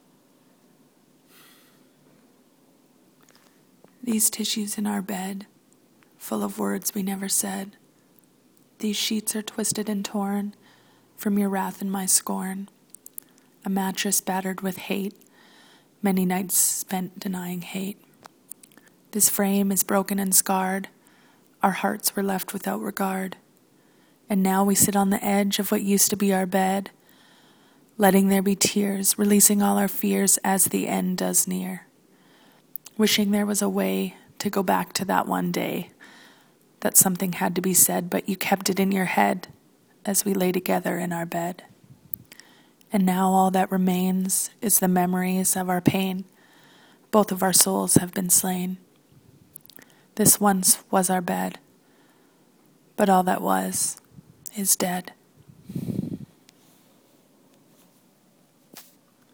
Our Bed (dramatic reading)